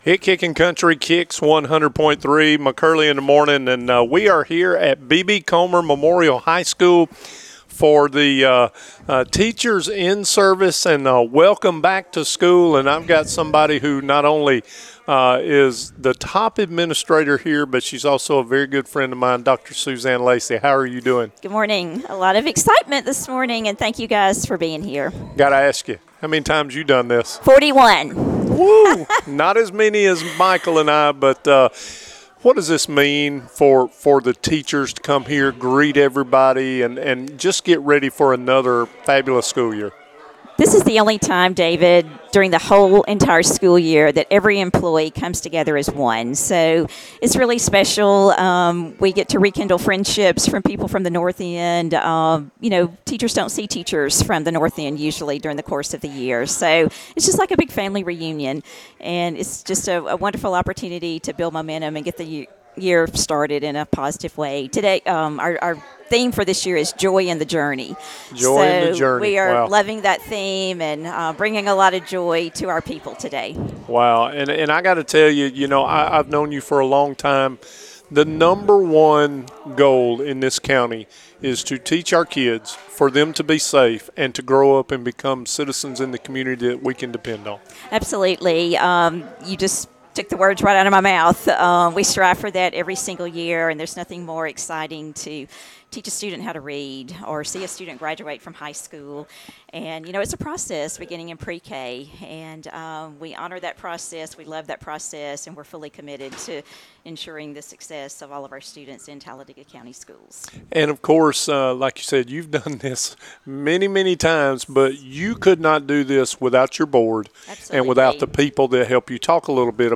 Live from the 2025 Talladega County Board of Education’s Institute Day